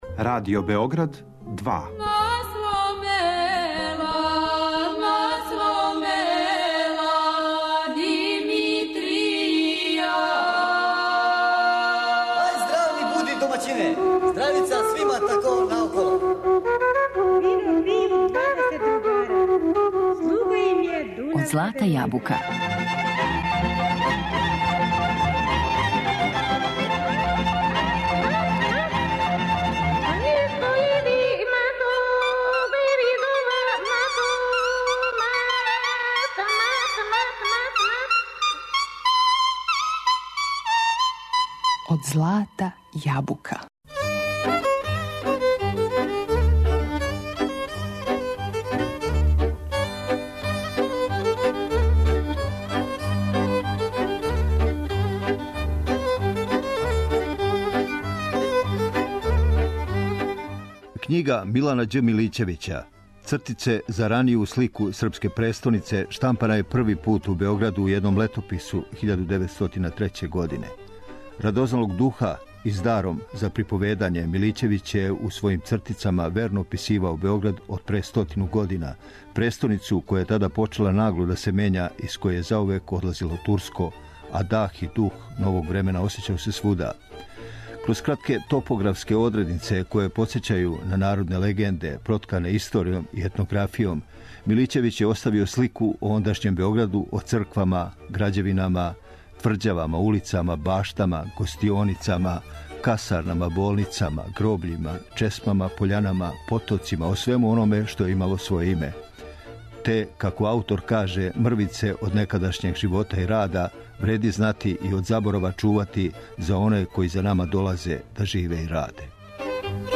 У њој је верно описан Београд пре стотину година, престоница која је почела нагло да се мења и у којој се осећао дах и дух новог времена. Текст приредио и читао новинар